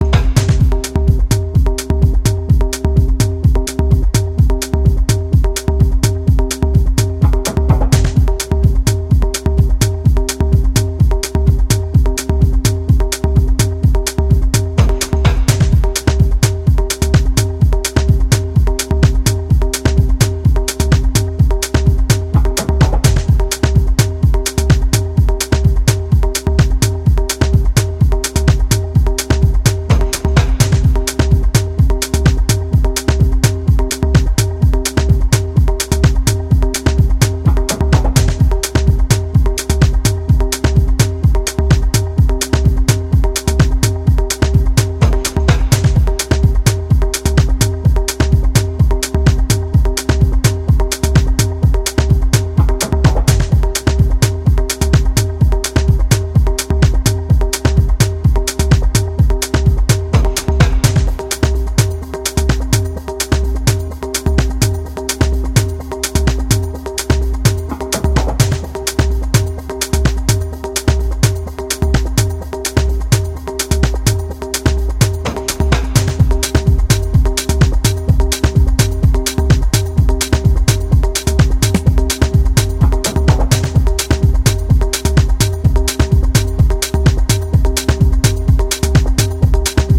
原曲要素を巧妙なフックへと据えたパーカッシヴな催眠ミニマル・テクノを展開しており、好調アクトの勢いが垣間見えます！